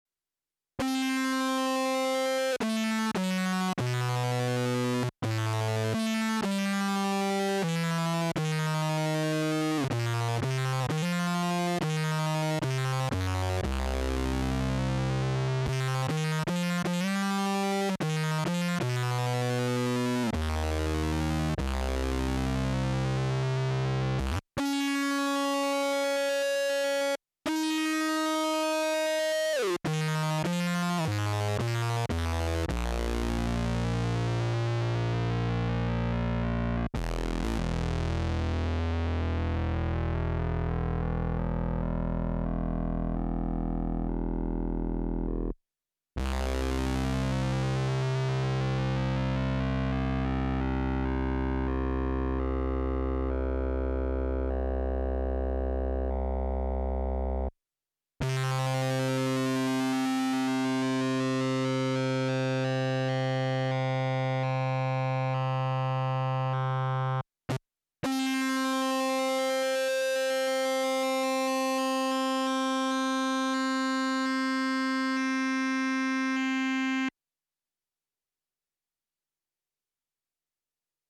Pseudo Bit Crush – Future Impact Program Database
QM+Pseudo+Bit+Crush.mp3